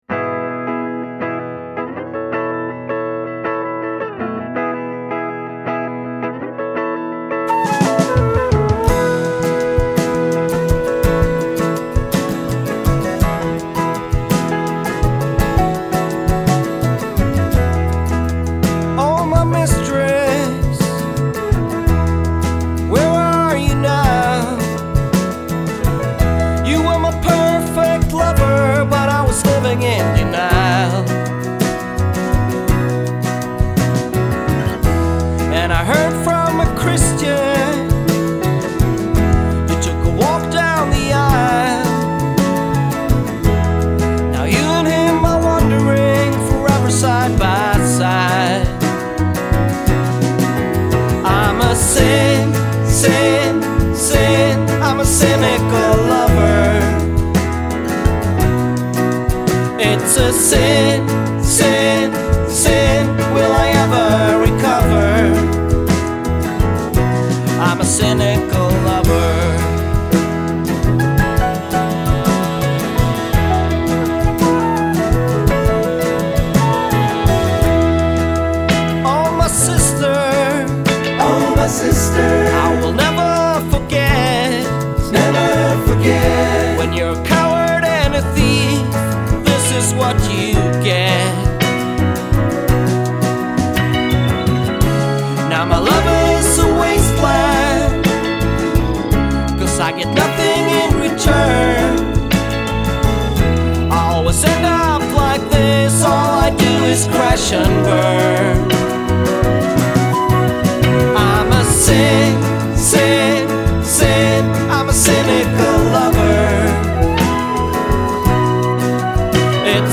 Tag: flute
nostalgic sing-along from Sweden
An airy, agile flute line sets the tone early